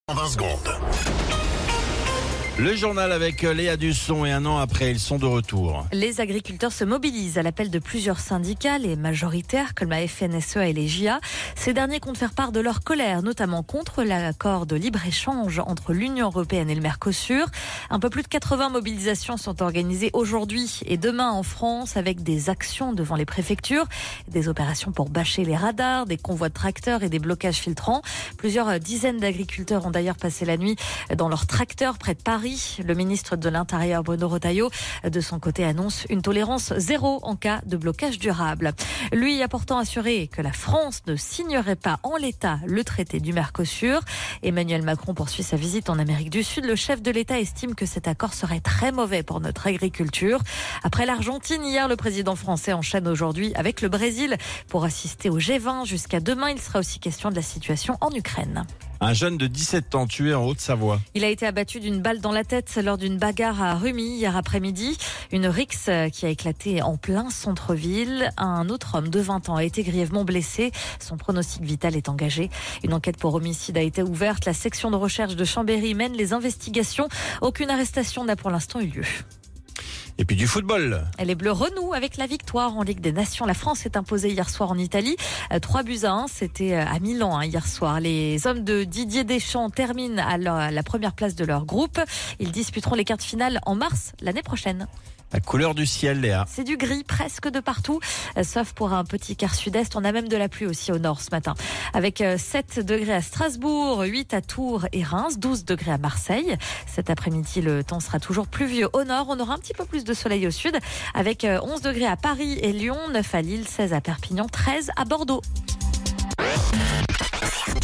Flash Info National 18 Novembre 2024 Du 18/11/2024 à 07h10 .